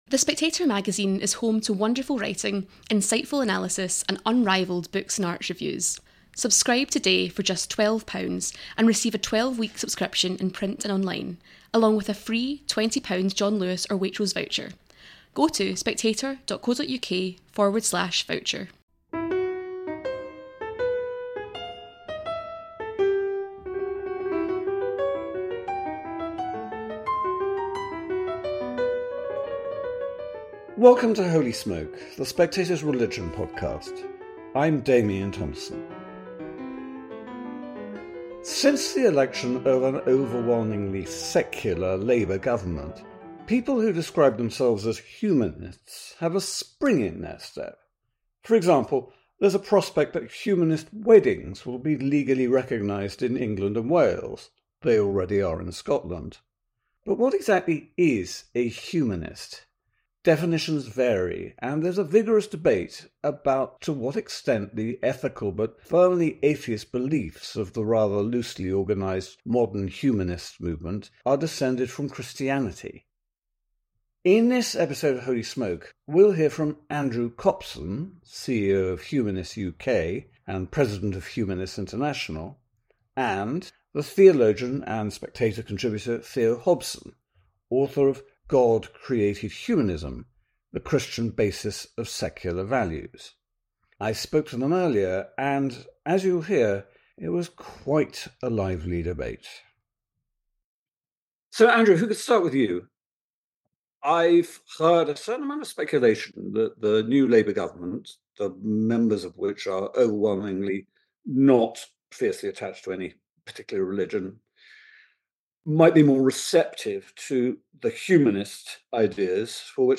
it was a lively encounter.